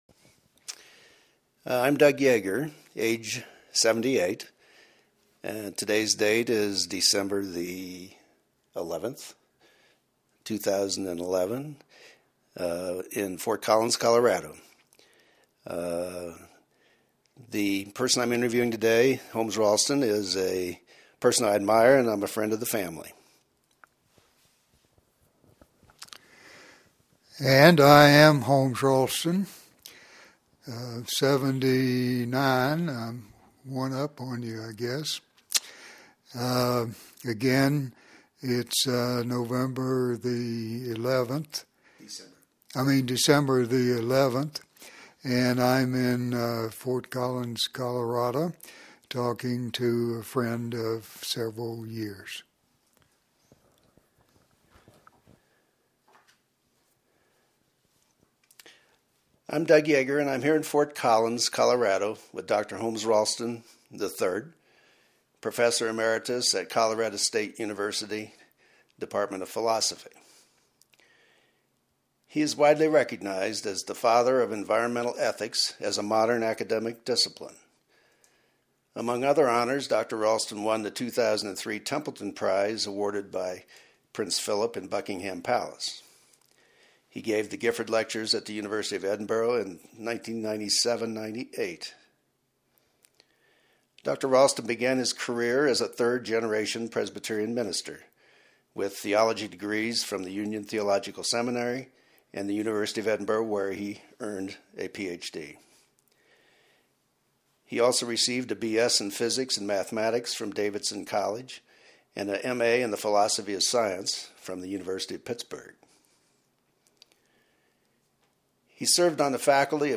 StoryCorps interview